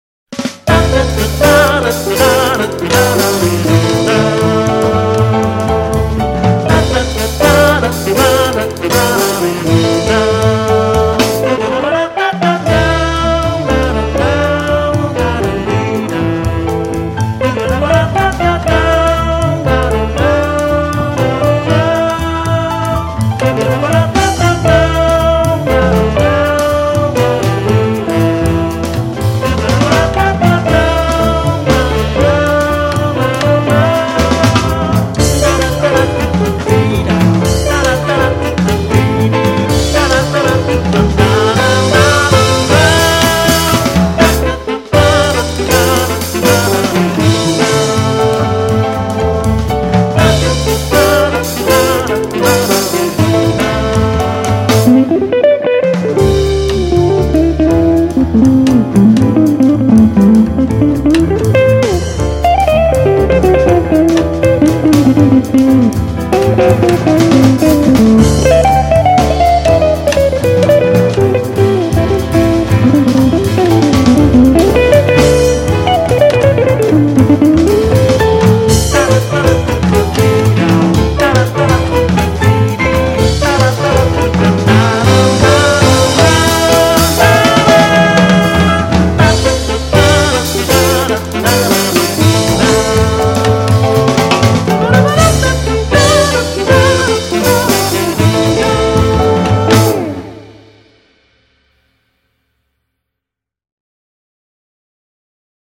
코러스가 어울리는 왈츠곡입니다.